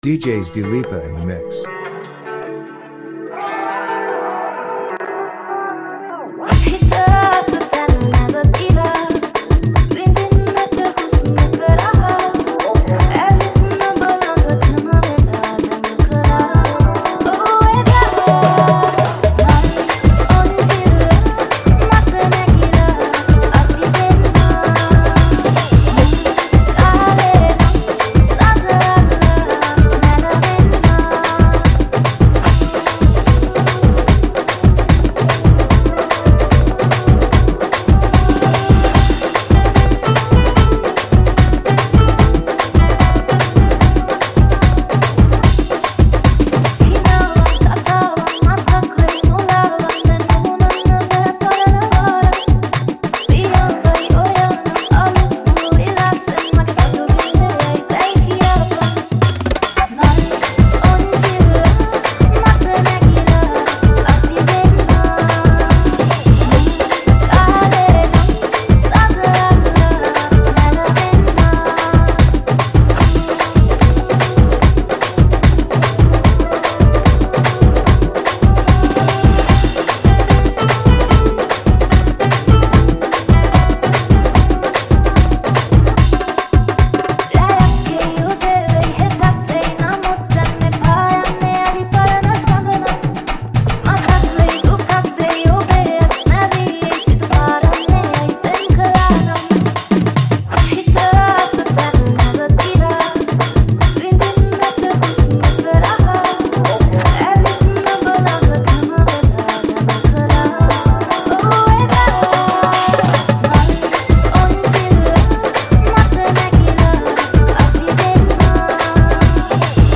High quality Sri Lankan remix MP3 (2.4).